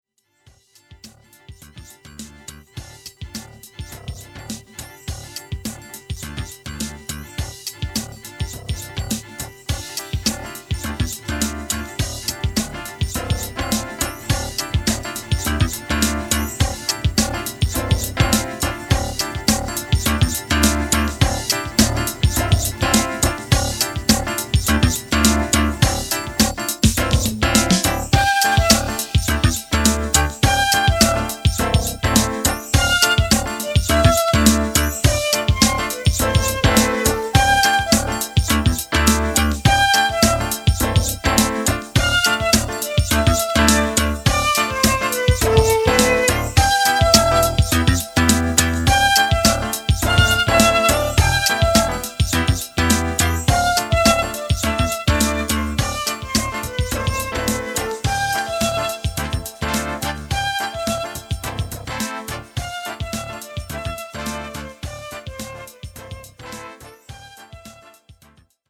Input of the drums, then of bass line and the piano.
all the orchestra begins again together.